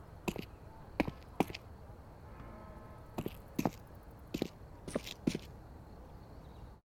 walk cs2 Meme Sound Effect
walk cs2.mp3